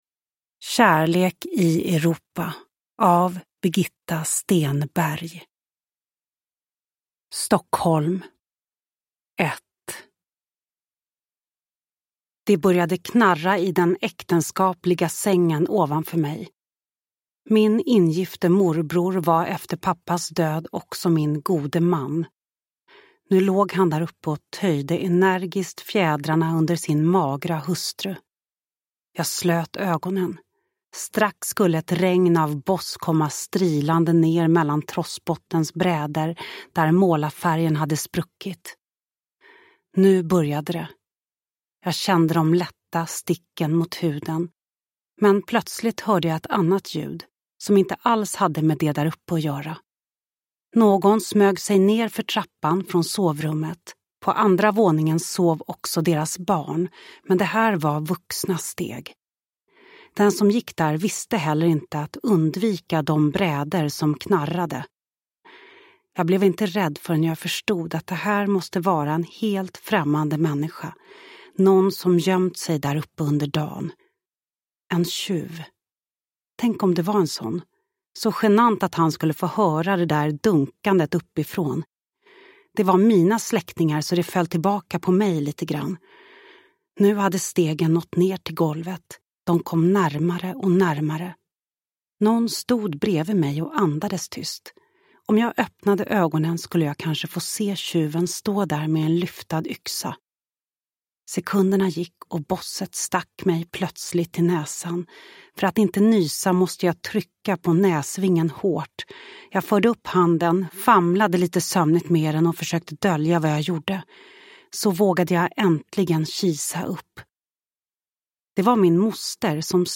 Kärlek i Europa – Ljudbok – Laddas ner
Uppläsare: Lo Kauppi